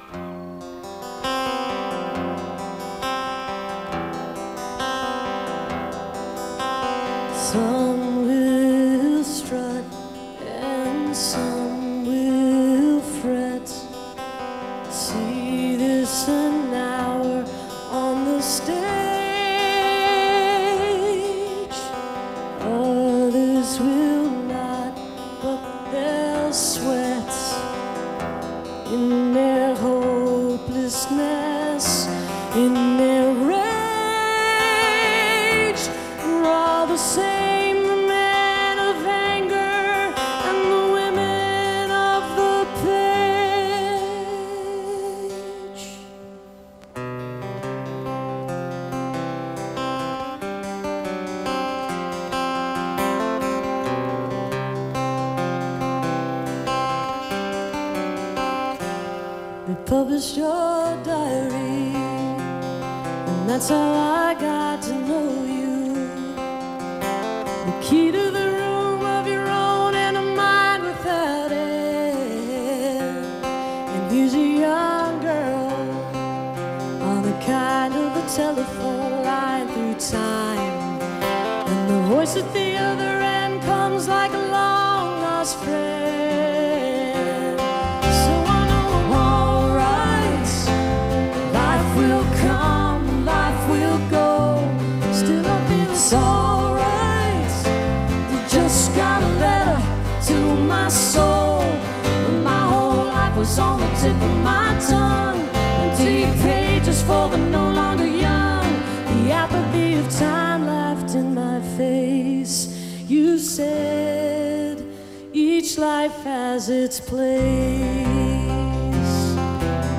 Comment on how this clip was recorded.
1993-08-21 autzen stadium - eugene, oregon